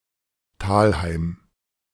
Talheim (German: [ˈtaːlhaɪm]